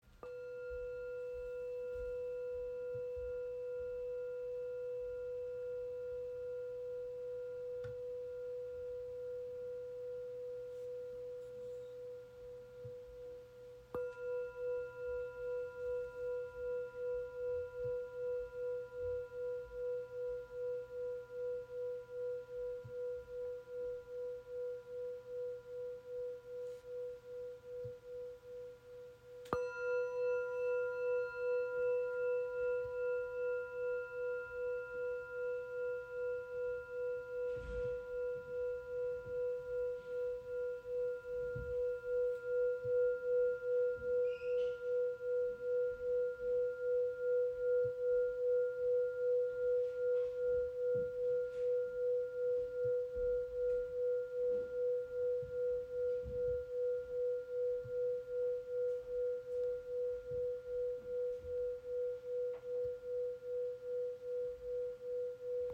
Klangglocke Terra in vier Grössen | erdige Resonanz | lange Schwingung
• Icon Sehr lange Resonanz mit besonders reinen Schwingungen
Handgeschmiedete Klangglocke in der Ausführung Terra mit warmen, erdenden Tönen, die Körper und Geist sanft zentrieren.
Schwebende Klangglocken aus der Bretagne
Ihr Klang ist klar, lichtvoll und rein. Er steigt auf wie ein feiner Atemzug und verweilt deutlich länger im Raum als der Klang klassischer Klangschalen.